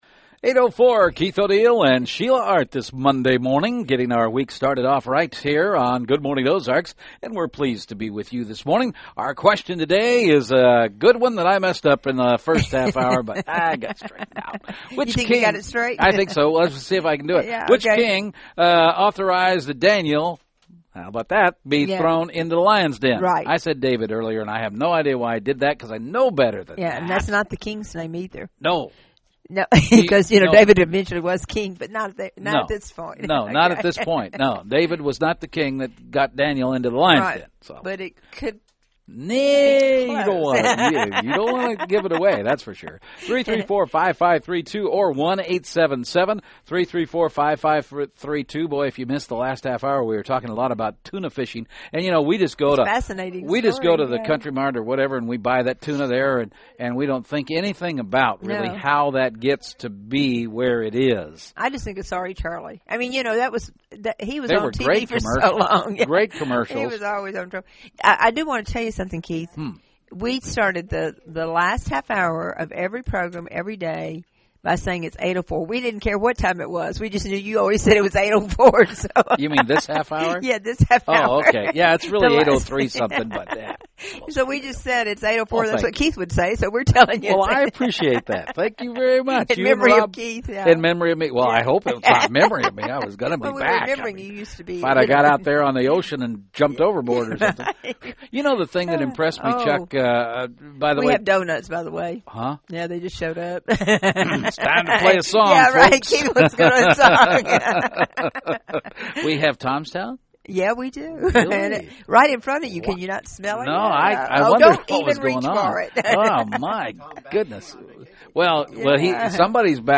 Horizon Church | Radio Interviews